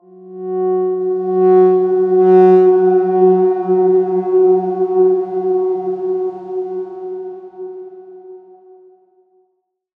X_Darkswarm-F#3-mf.wav